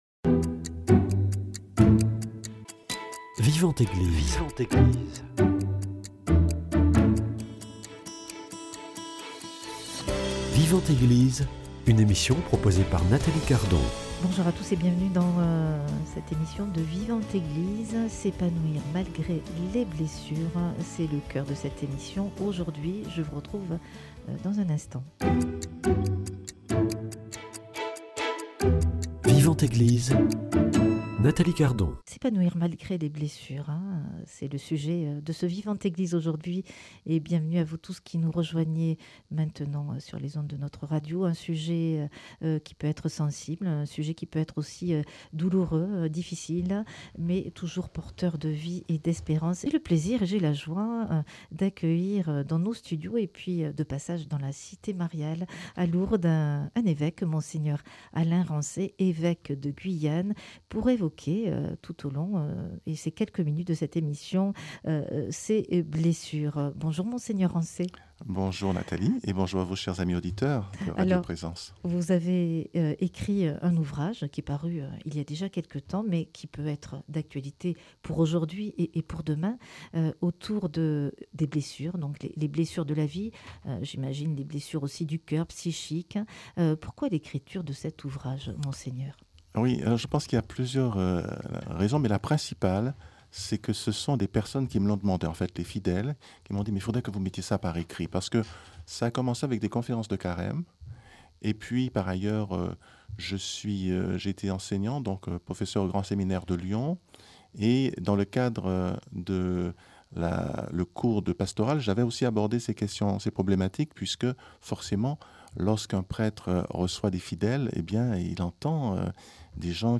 Au micro Monseigneur Alain Ransay, évêque de Guyane et auteur du livre S'épanouir malgré les blessures, il invite à relire nos blessures à la lumière de la foi, trouver du sens dans la souffrance et avancer sans être prisonnier de nos cicatrices. De la confrontation avec la douleur à la reconstruction intérieure, Monseigneur Ransay nous partage des pistes concrètes pour retrouver la résilience, la paix intérieure et une vie féconde, en s'appuyant sur la prière, l'Évangile et la communauté chrétienne.